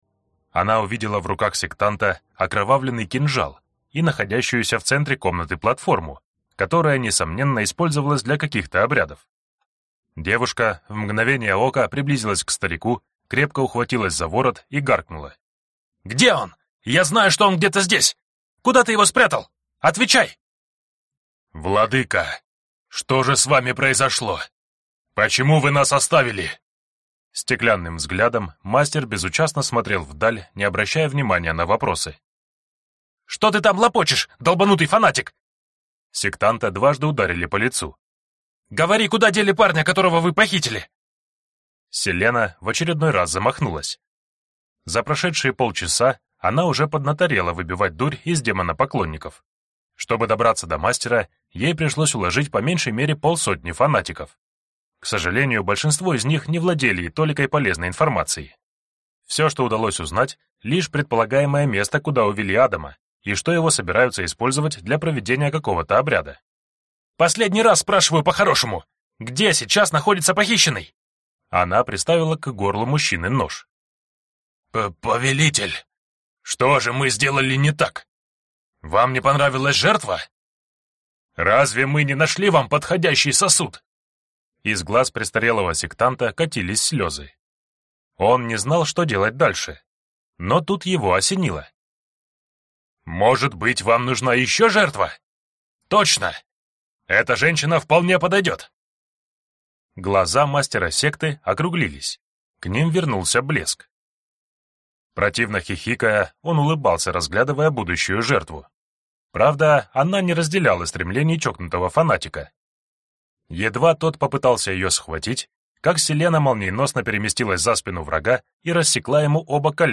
Аудиокнига Копатель. Книга 3 | Библиотека аудиокниг